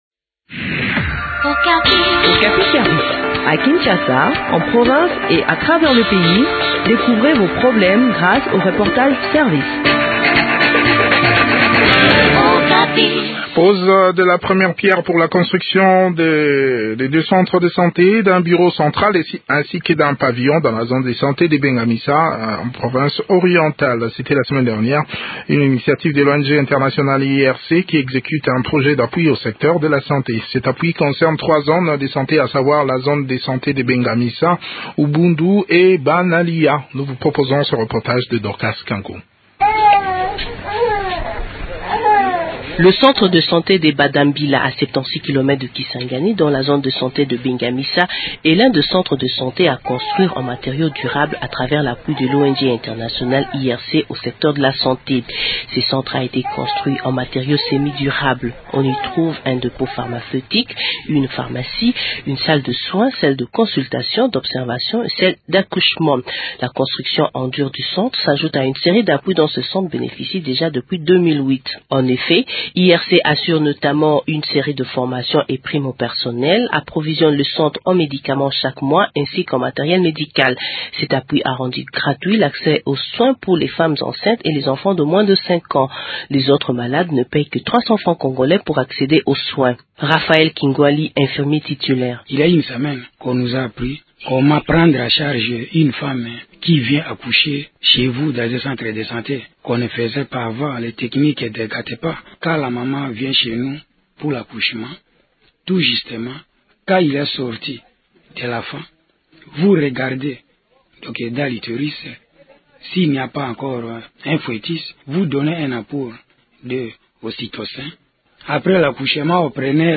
s’entretient sur les travaux qui seront faits